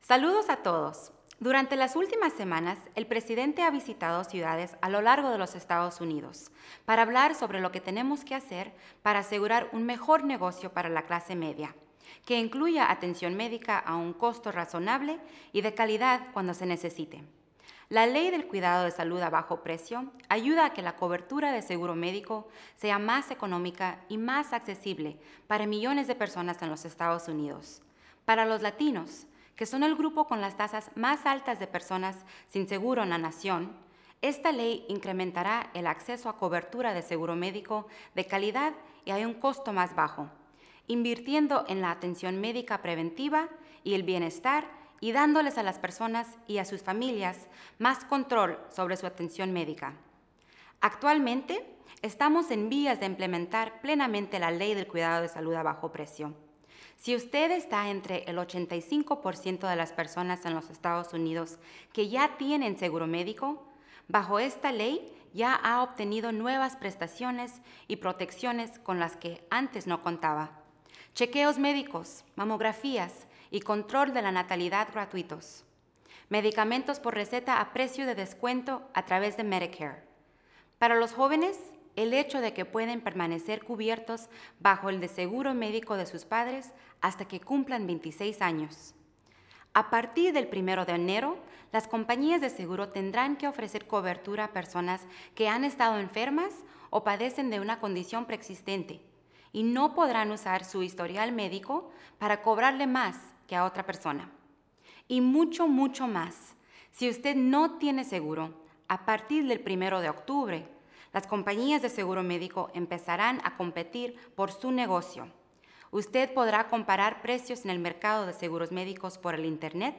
La Directora de Políticas de Salud Pública del Departamento de Salud y Servicios Humanos Mayra Álvarez habla sobre la Ley de Cuidado de Salud a Bajo Precio y su impacto sobre las personas en los Estados Unidos